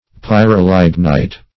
Pyrolignite \Py`ro*lig"nite\, n.